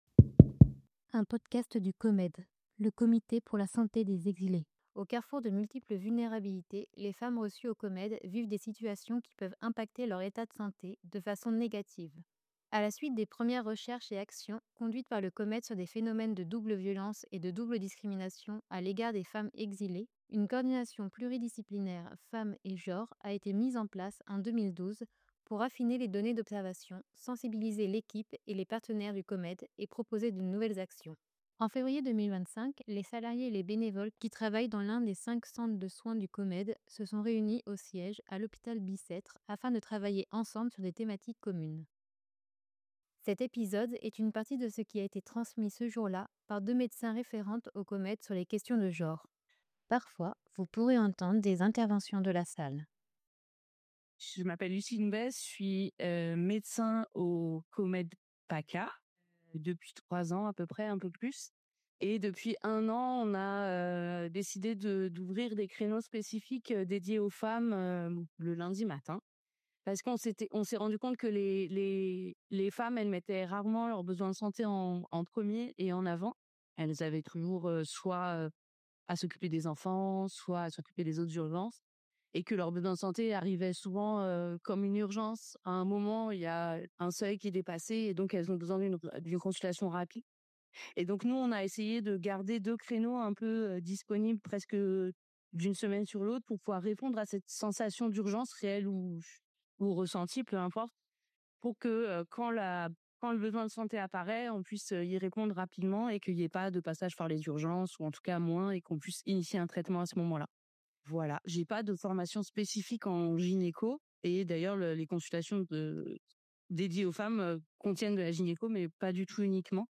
En février 2025, les salarié·es et les bénévoles qui travaillent dans l’un des 5 centres de soins du Comede se sont réuni·es au siège, à l’hôpital Bicêtre, afin de travailler ensemble sur des thématiques communes. Un après-midi entier a été consacré au sujet de l’accompagnement des femmes.
A l’occasion de la journée internationale des droits des femmes, nous vous proposons dans ce nouvel épisode de podcast, une partie de ce qui a été transmis ce jour-là par deux médecins référentes au Comede sur les questions de genre.